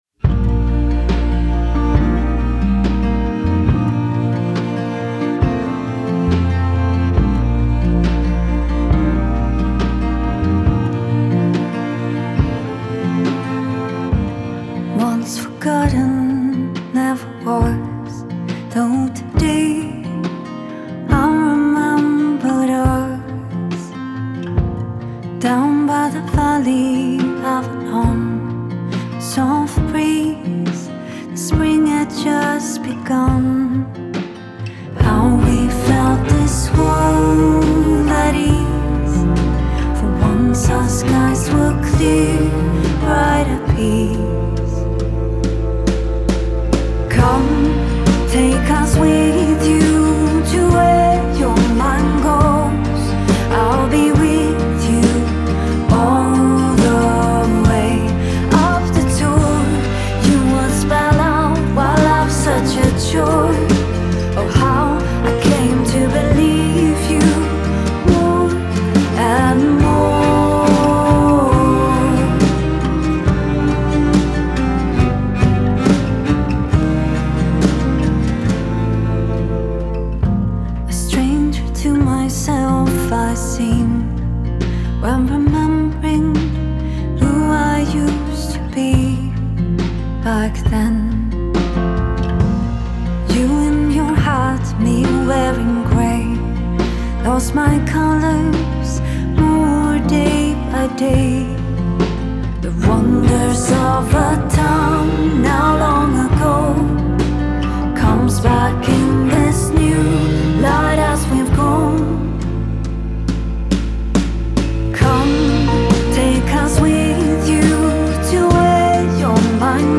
Scandinavian singer-songwriter
recorded at London’s Colibri Sound Recorders